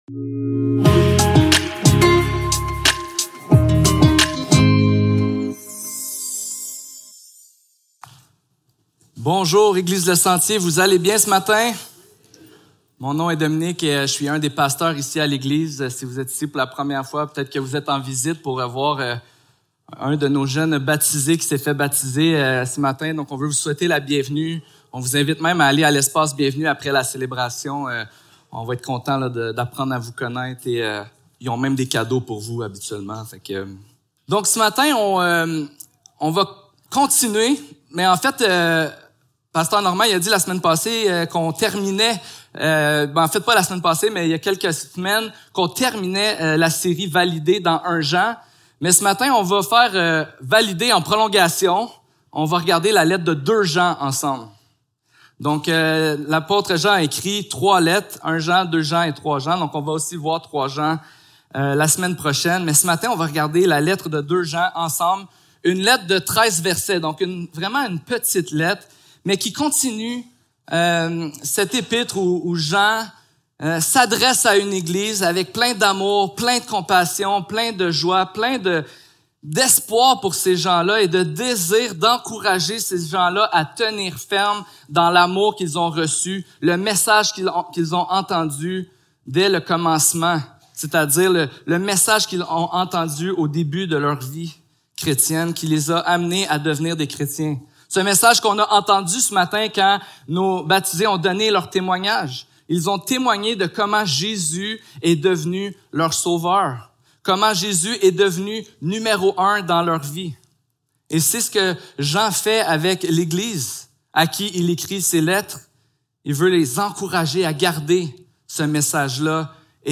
2 Jean Service Type: Célébration dimanche matin Description